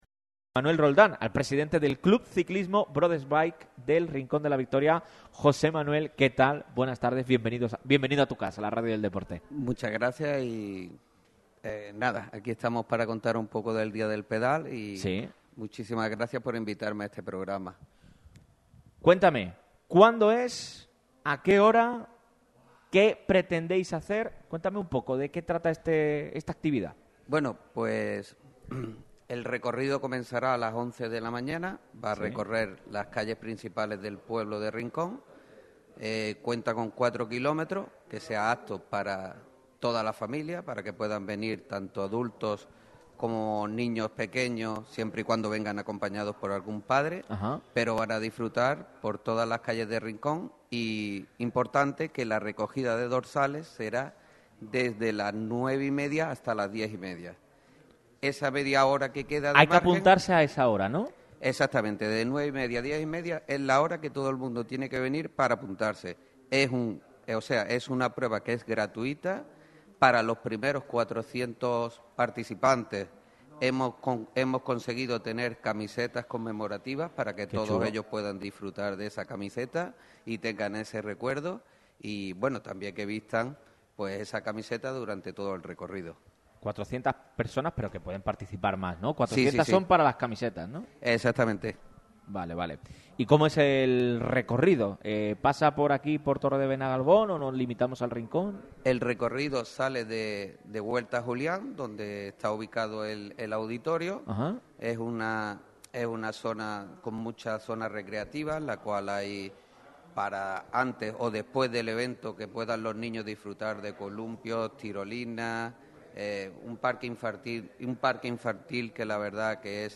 La emisora líder de la radio deportiva malagueña ha llevado a cabo este viernes 24 de octubre un nuevo programa especial en un lugar con mucha historia. Radio MARCA Málaga se ha desplazado al museo arqueológico de Villa Antiopa en Torre de Benagalbón (Rincón de la Victoria).